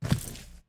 Footstep_Concrete 04.wav